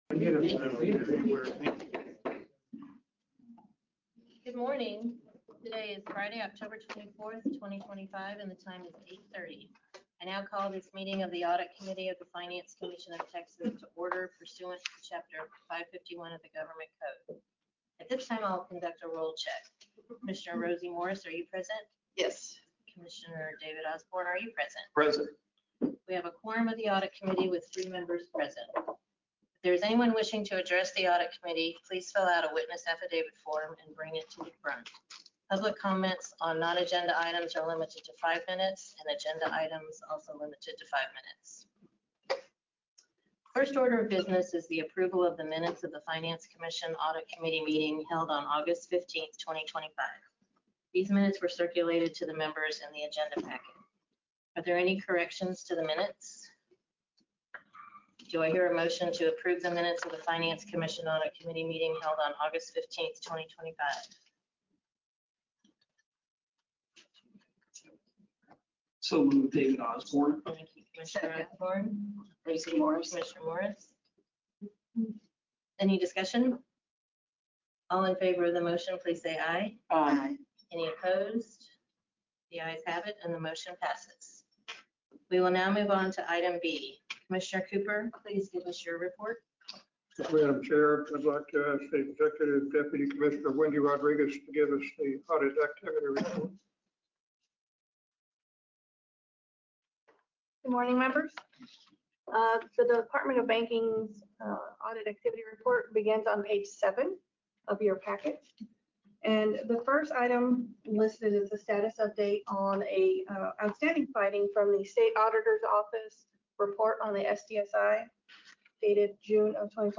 via Webinar